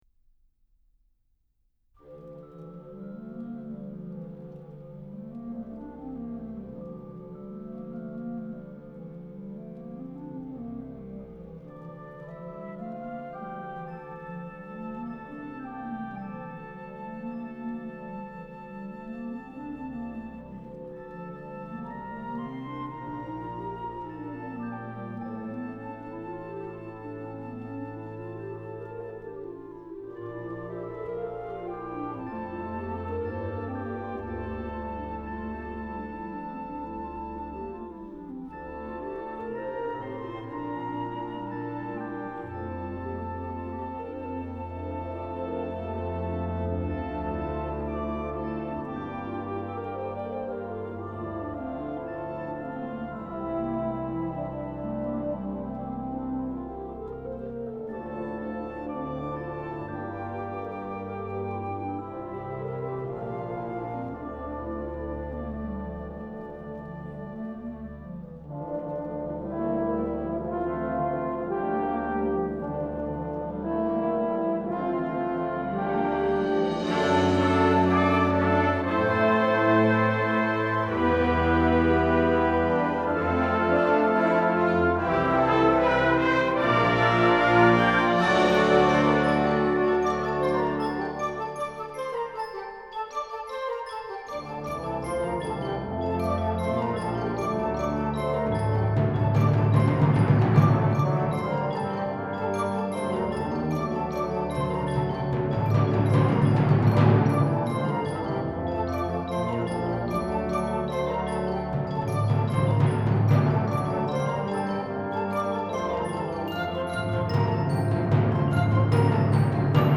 Genre: Band
Timpani (4 drums)
Percussion 3 (vibraphone, chimes, large tam-tam)